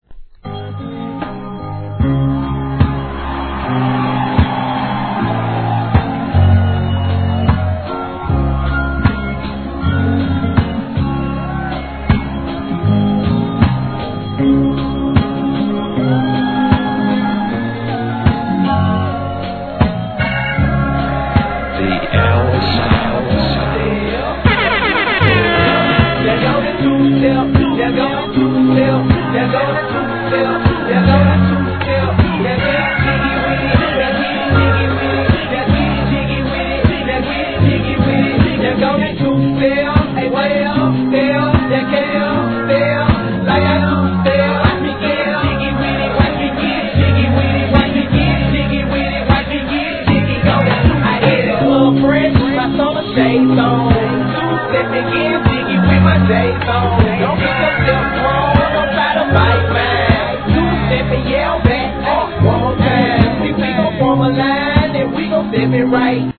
1. HIP HOP/R&B
これは使えるMIX〜MUSHUP,ブレンド物!!